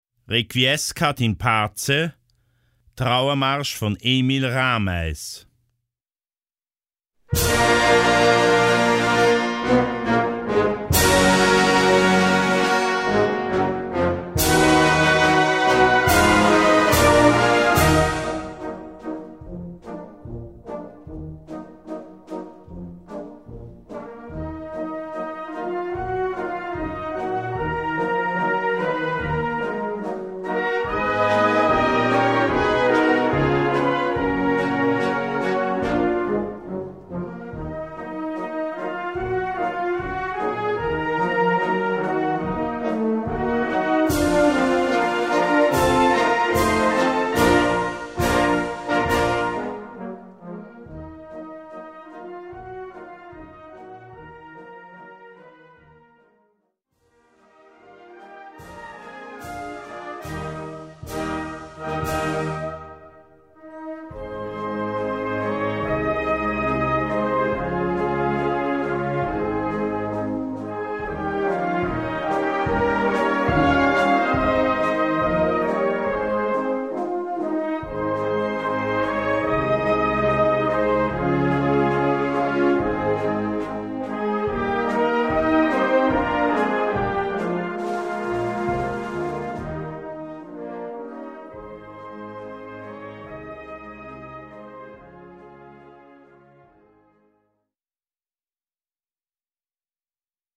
Gattung: Trauermarsch
Besetzung: Blasorchester